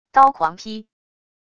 刀狂劈wav音频